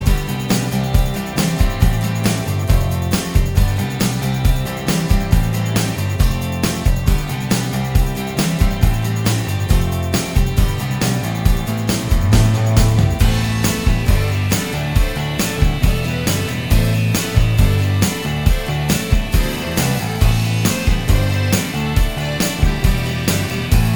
Minus Guitar Solos Pop (1980s) 3:19 Buy £1.50